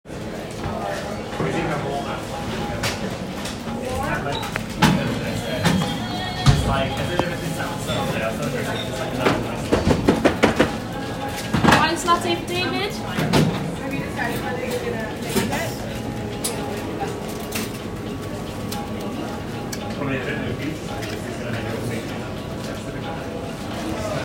I went on several sound walks and found myself in areas that were more tucked away or not as obvious to the daily commuter.
The first was on the top of a four-story car park and the other in a busy student café at midday.
The abrupt and cluttered chaos of the café sketch felt overwhelming to capture and create and on reflection I think this is obvious from the harsh, jaggedness of my drawing.
Study Cafe Sketch